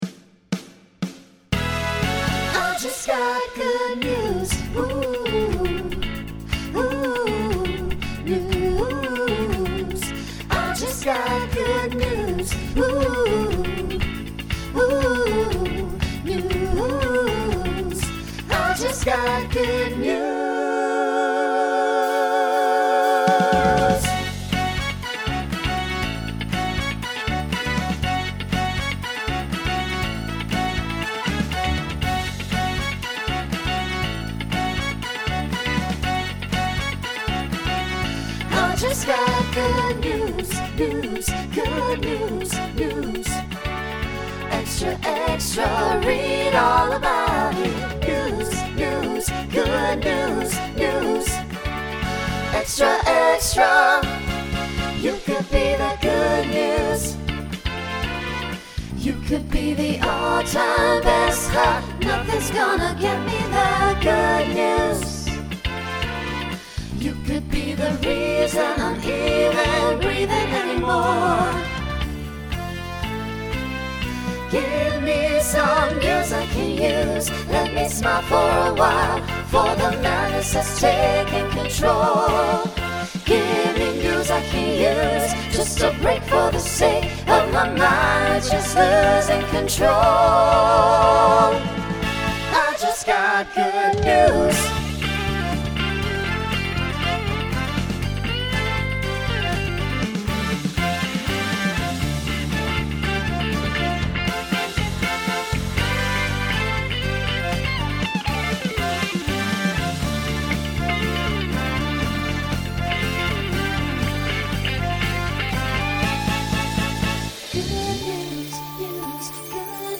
Pop/Dance , Rock
Voicing SATB